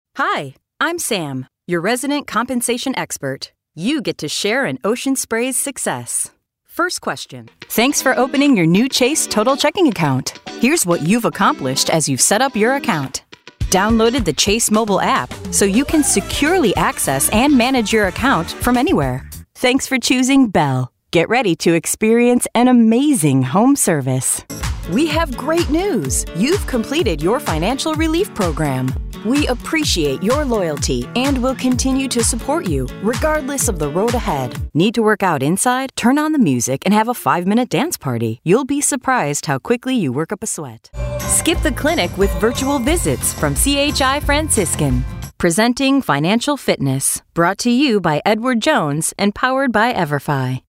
E-learning
Micrófono MKH 415, Pro Tools, estudio insonorizado con funciones de masterización.